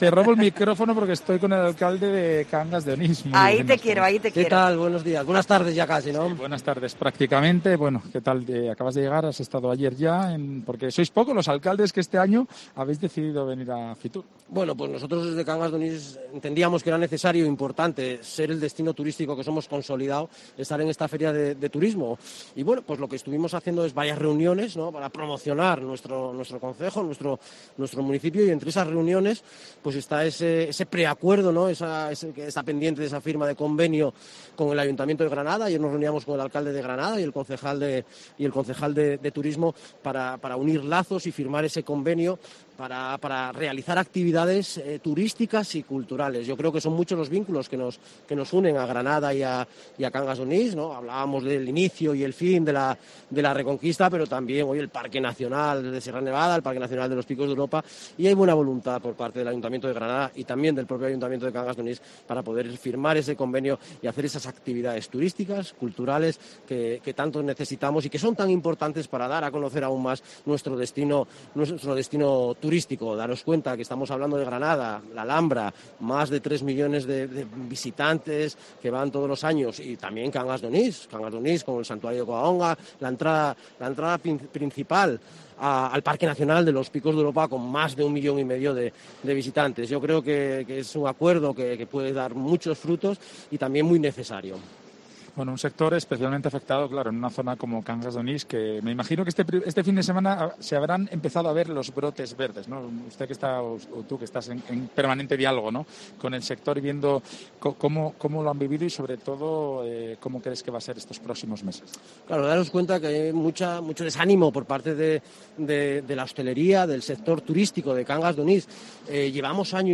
Entrevista al alcalde de Cangas de Onís, José Manuel González